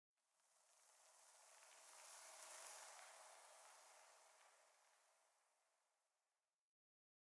1.21.5 / assets / minecraft / sounds / block / sand / sand18.ogg
sand18.ogg